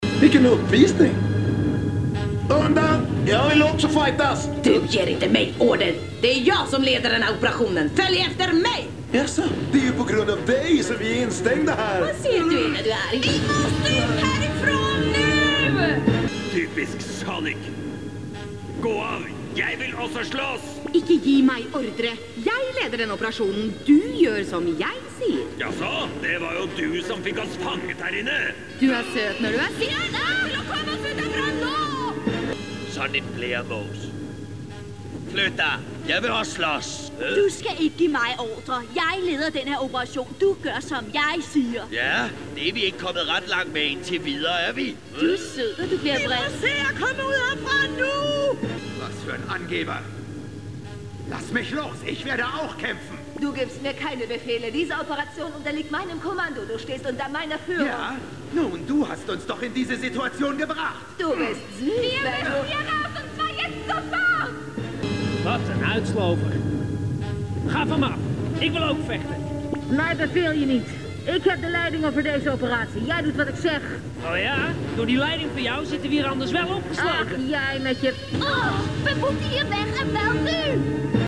The language order in this clip is Swedish, Norweigan, Danish, German and finally Dutch. Sounds ultra-crazy...!!
My sound equipment is not that great, so you may have to make the sound a bit louder as you play the clips.